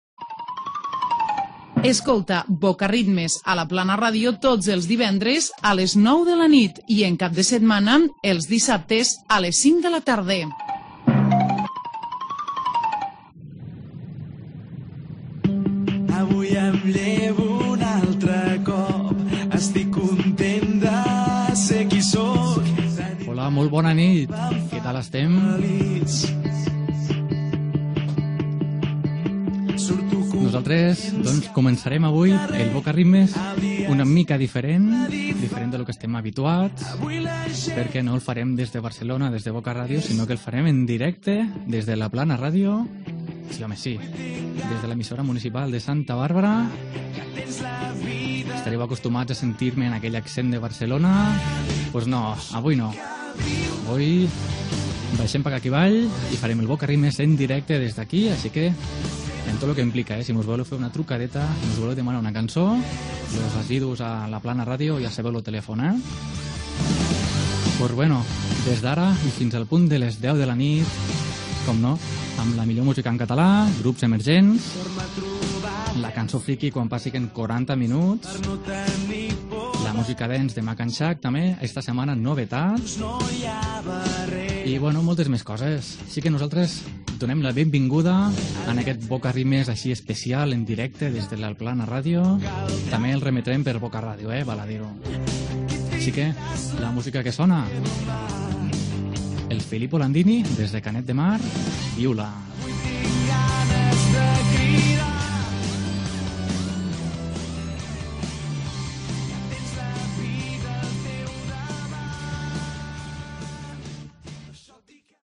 Indicatiu del programa, presentació.
Musical